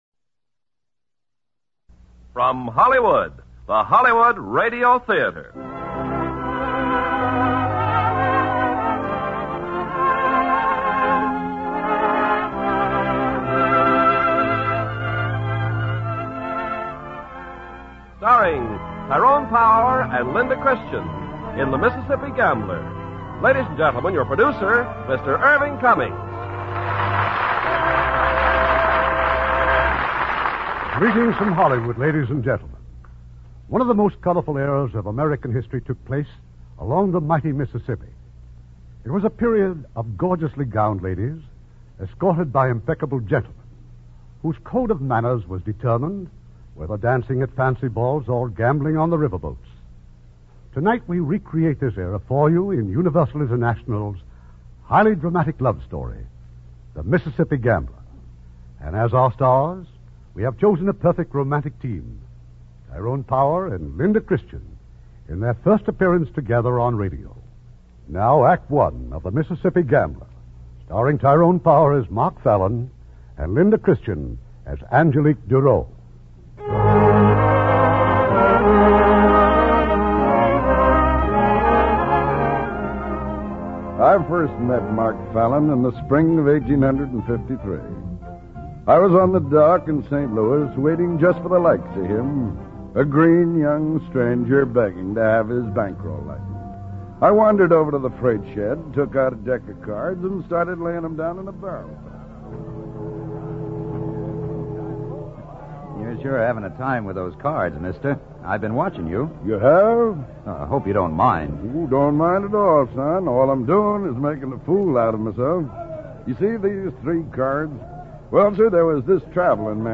starring Tyrone Power, Linda Christian
Lux Radio Theater Radio Show